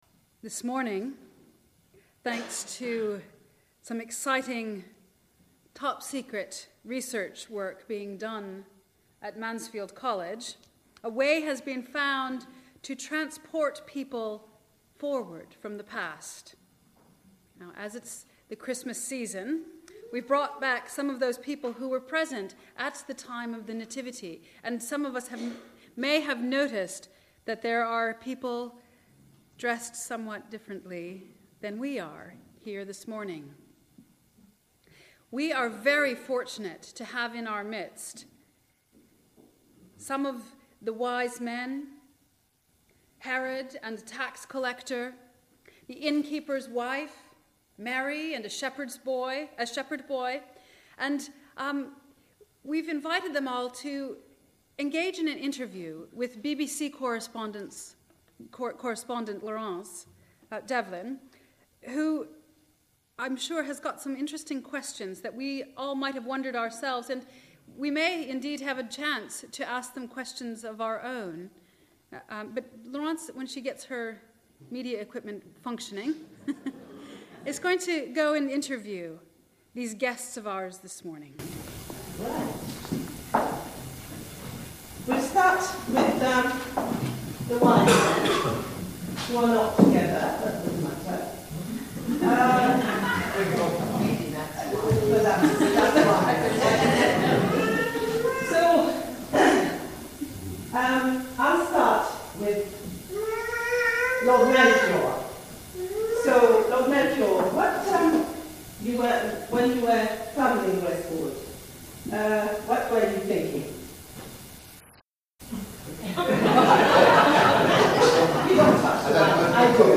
Service: Sunday Morning
Worship Leader : Members of the Congregation